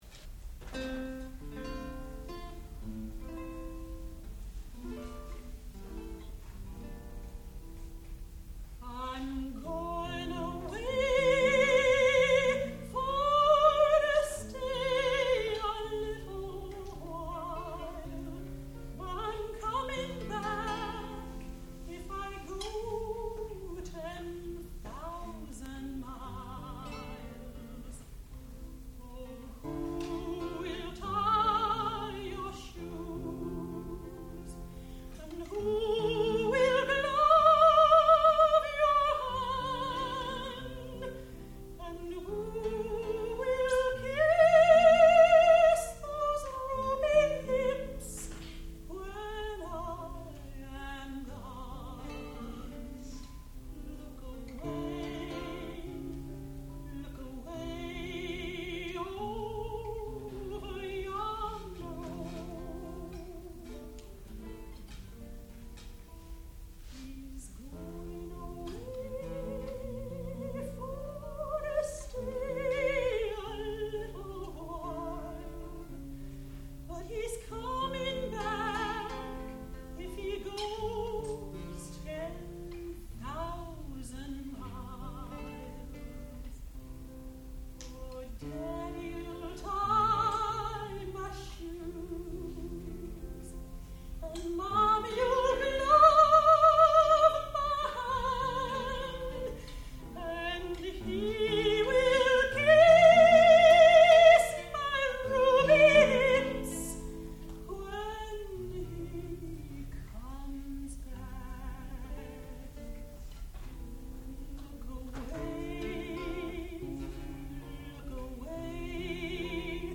American folk songs
dulcimer, guitar and banjo
soprano